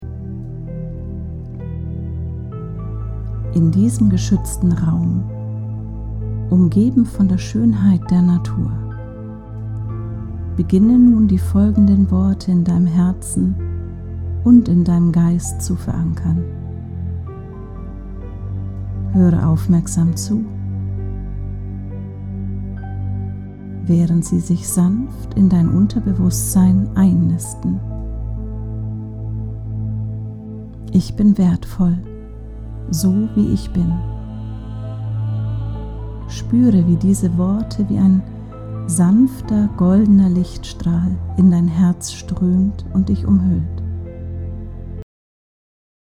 – Positive Affirmationen: Verinnerliche kraftvolle Botschaften, die dich unterstützen und motivieren. 🎶💬
Diese Hypnose beinhaltet auch eine Visualisierungsübung.